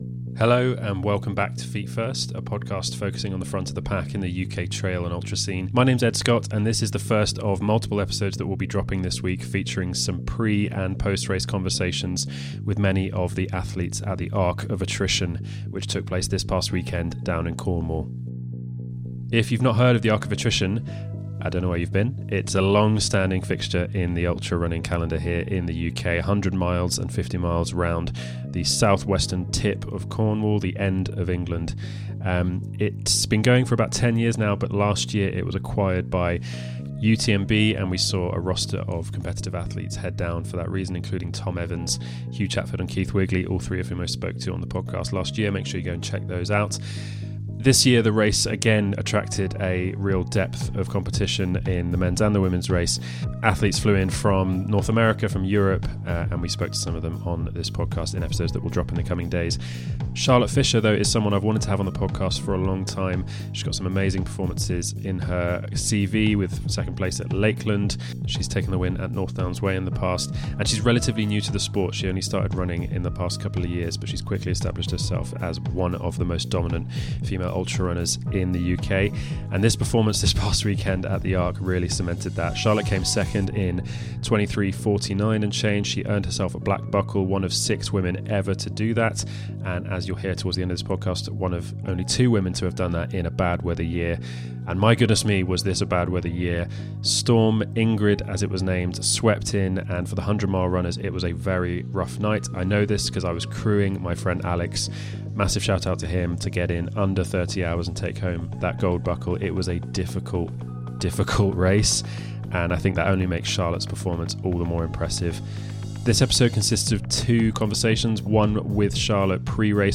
A pre- and post-race chat